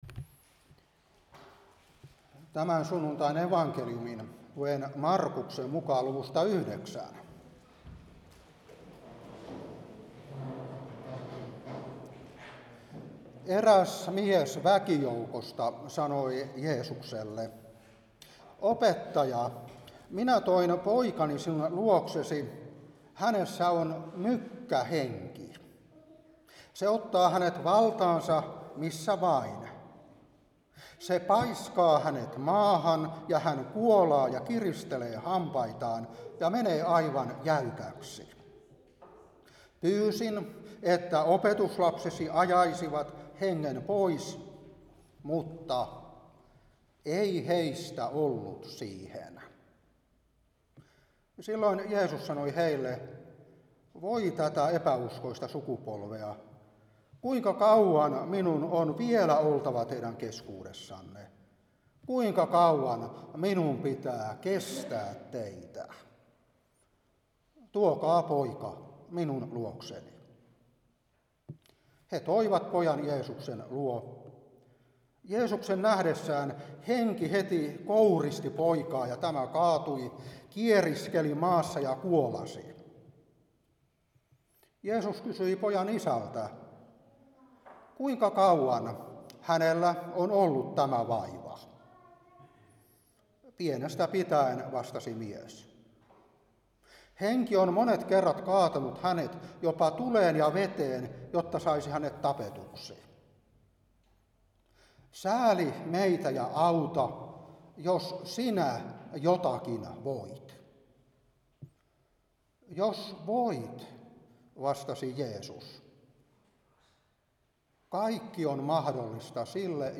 Saarna 2026-3 Mark. 9:17-29